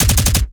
GUNAuto_Assault Rifle D Burst_05.wav